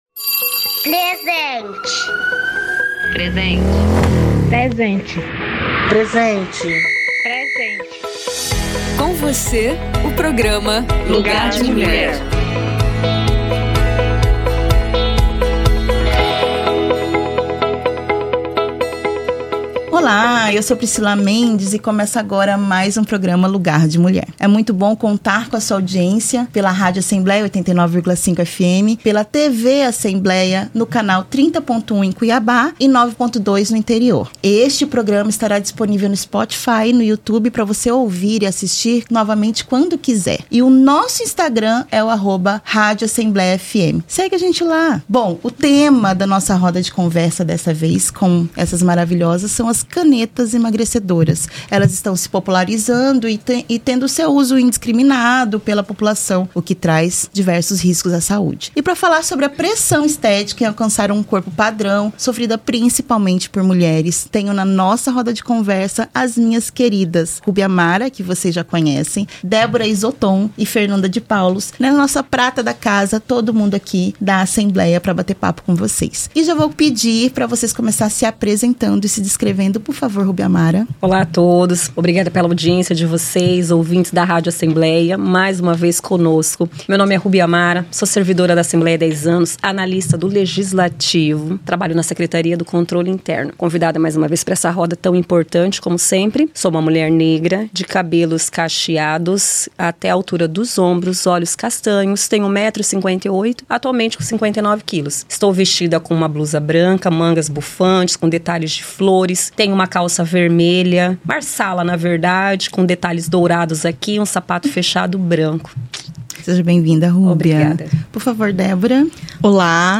Lugar de Mulher - 65 - Roda de Conversa - Canetas emagrecedoras e pressão estética